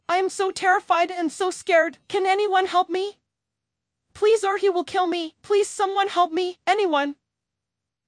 terrified.wav